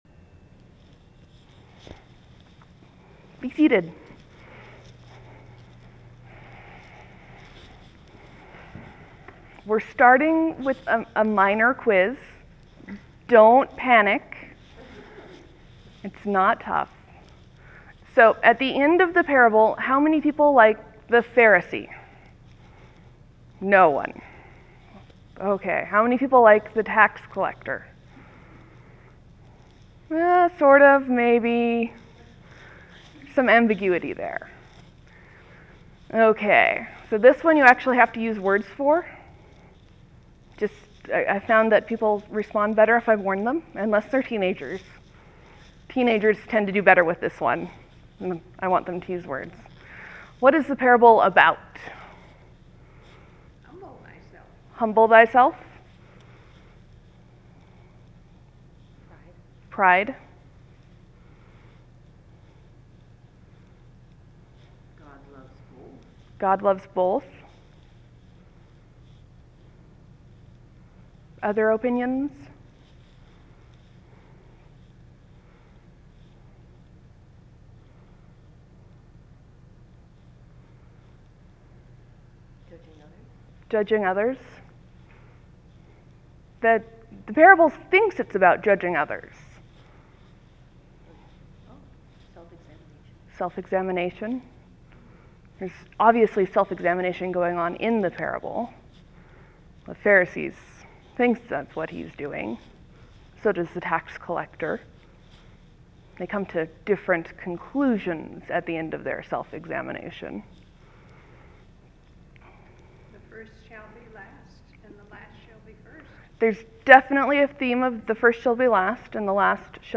A sermon on righteousness Click to listen:Proper 25, Year C 2010